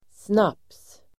Ladda ner uttalet
snaps substantiv, dram , schnapps , snaps Uttal: [snap:s] Böjningar: snapsen, snapsar Synonymer: hutt, nubbe Definition: litet glas brännvin, sup Sammansättningar: snaps|visa (drinking song), snaps|glas (dram glass)